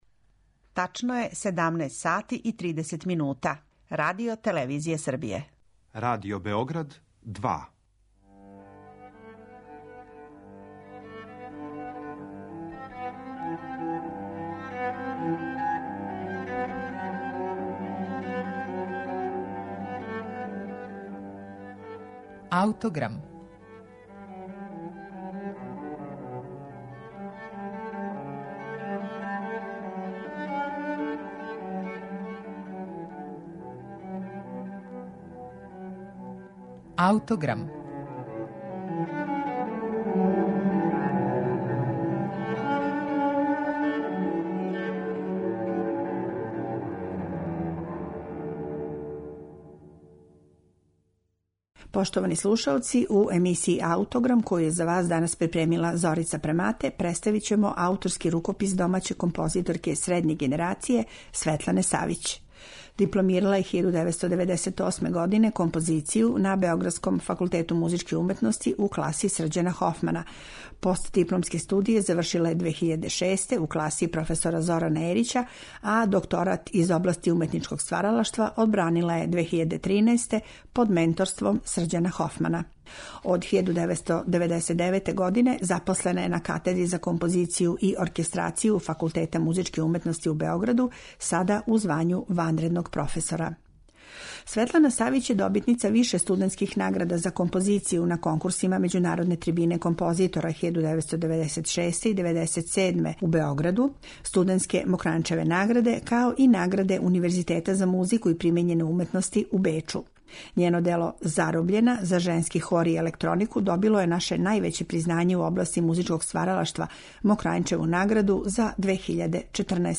циклус за мецосопран, виолончело и миди-клавијатуру.
миди-клавијатура